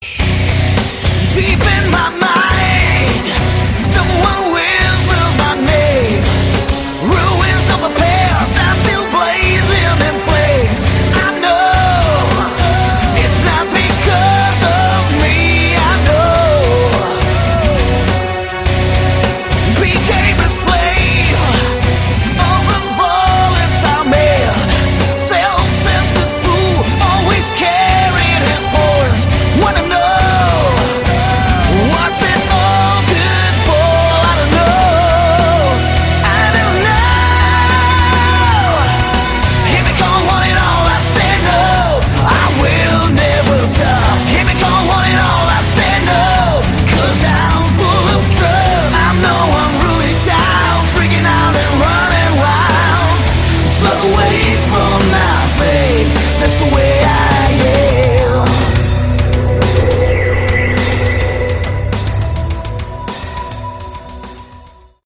Category: Melodic Hard Rock
guitars, bass, programming
keyboards